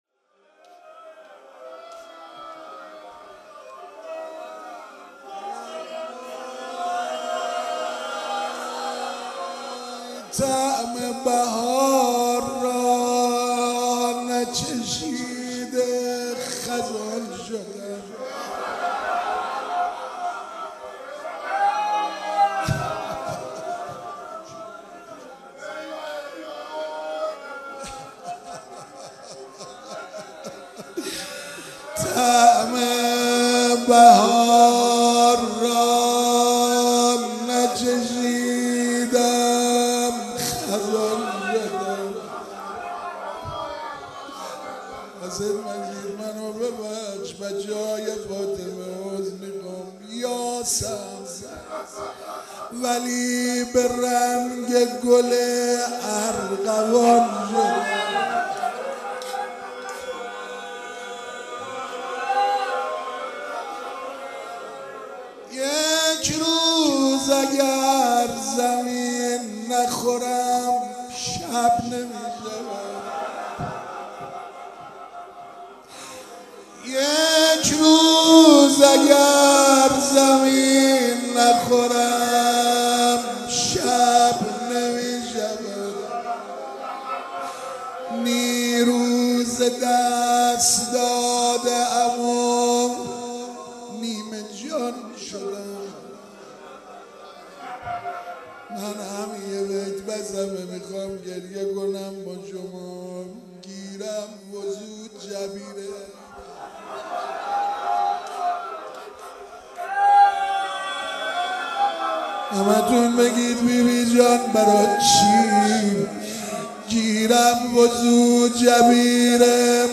شــــب اول فـاطــمـیـه اول
روضه